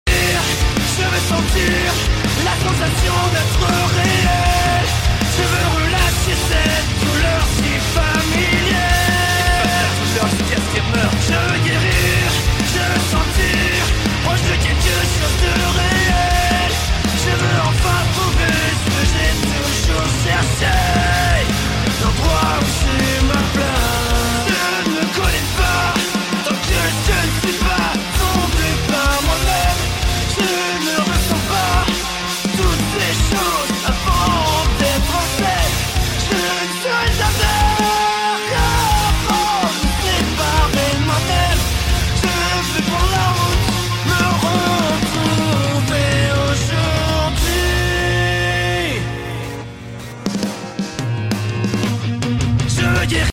drum cover
french singing cover
🥁 Electronic drum kit